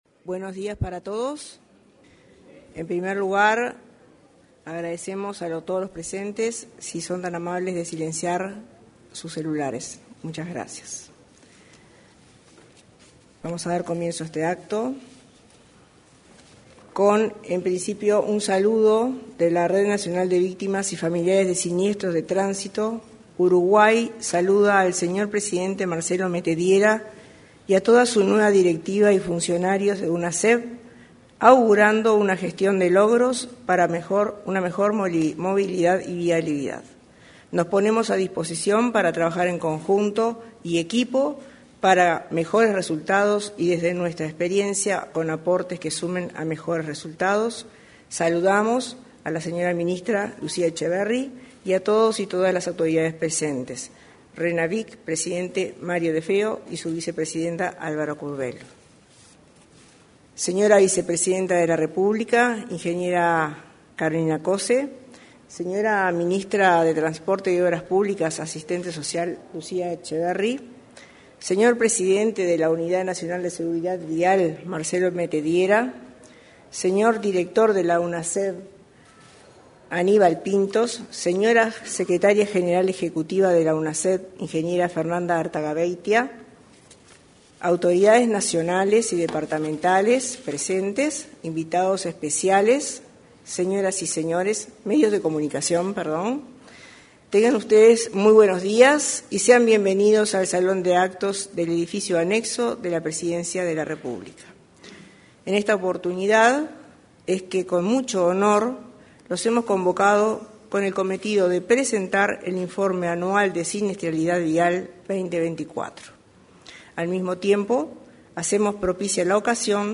En la oportunidad, se expresaron, por la Unidad Nacional de Seguridad Vial (Unasev), su presidente, Marcelo Metediera; la secretaria general ejecutiva, María Artagaveytia, y el director Aníbal Pintos. Culminó con su oratoria, la ministra de Transporte y Obras Públicas, Lucía Etcheverry.